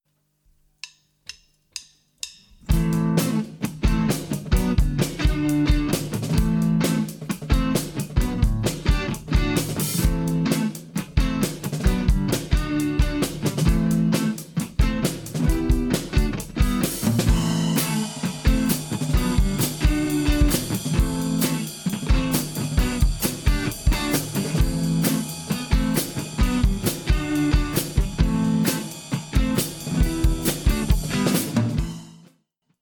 Das erste Soundergebnis des Microtest hat mich begeistert, bis mir der Tontechniker sagte, krieg dich wieder ein, das war nur der Test.